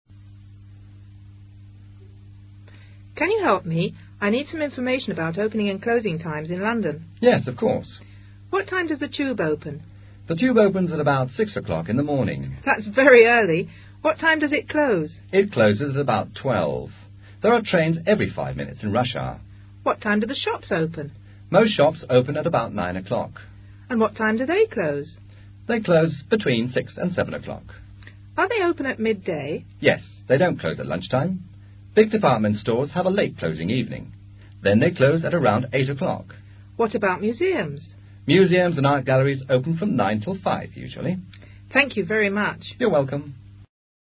Mediante el diálogo adjunto es posible reforzar el uso del enunciado what time does X open / close, junto a terminología relativa a las ciudades, el transporte y el tiempo.